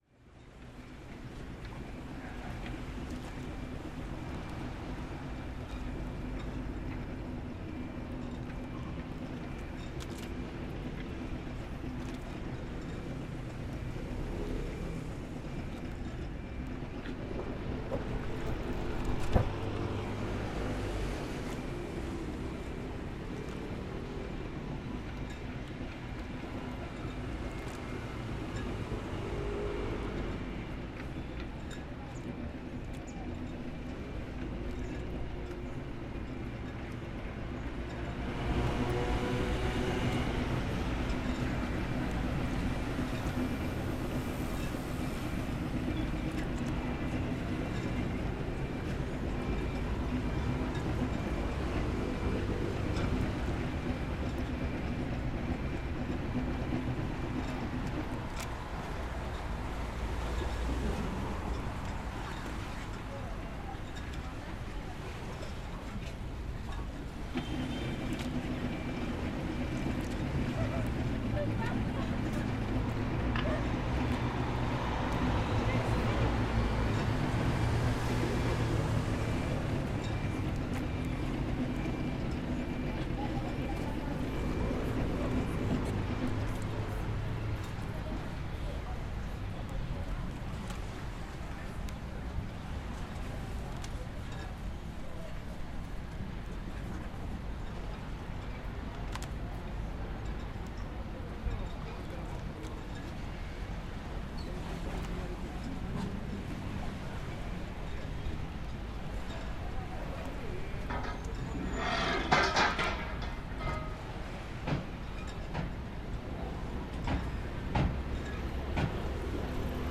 boats | Framing sounds
Field Recording-Near the harbor in San Remo